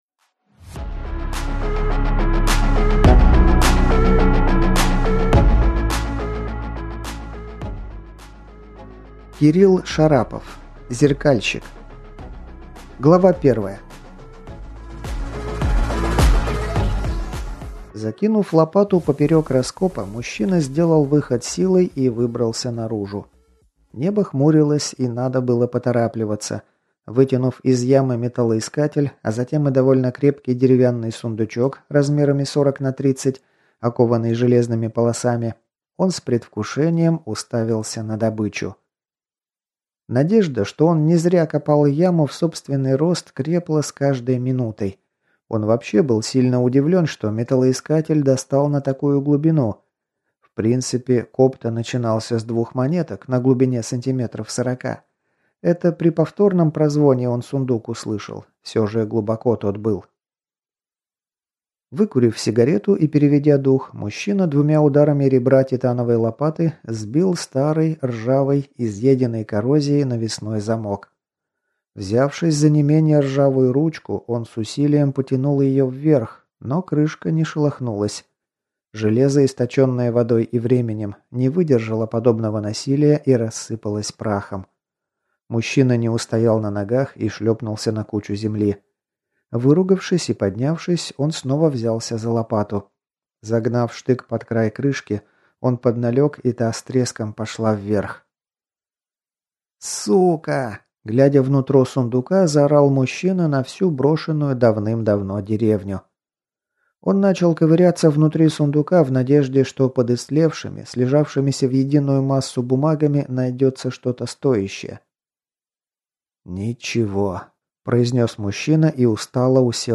Слушать аудиокнигу Не судьба полностью